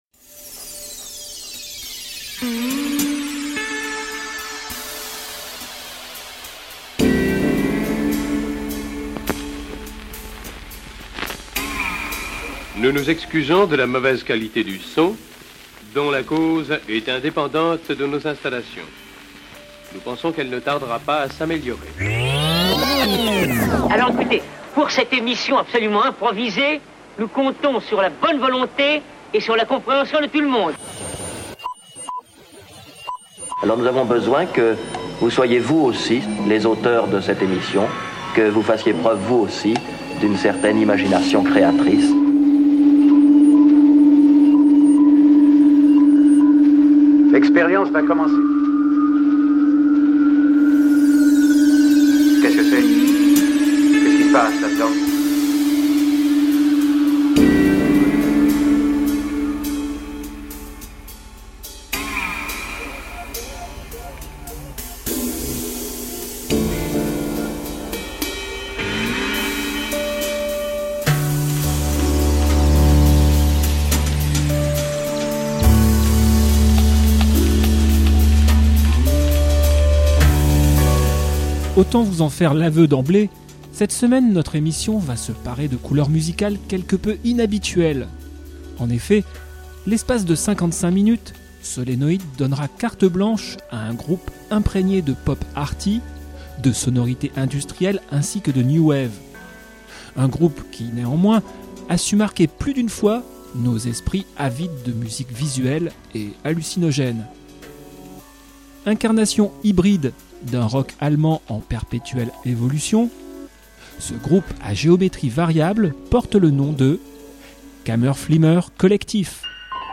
Entre folk écorchée et rock oblique